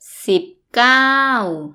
_ sibb ∧ gau